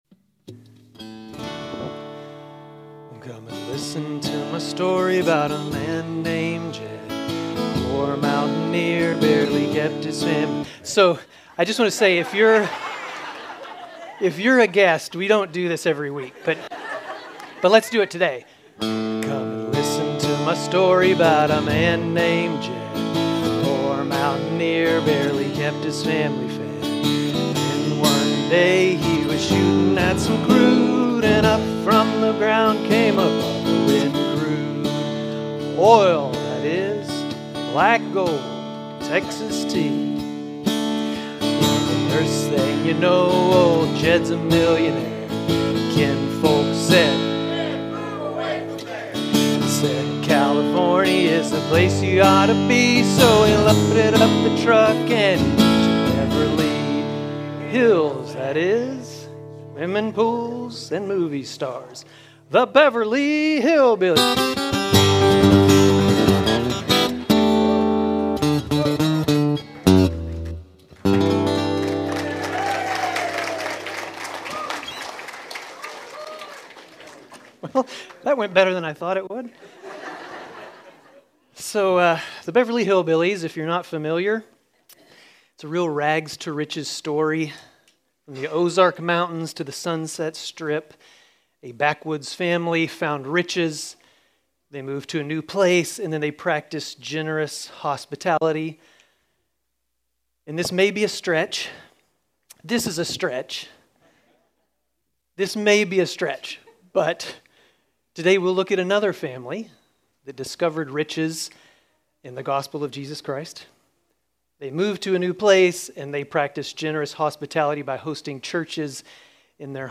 Grace Community Church Dover Campus Sermons 8_3 Dover Campus Aug 04 2025 | 00:26:33 1x 00:00 / 00:26:33 Subscribe Share RSS Feed Share Link Embed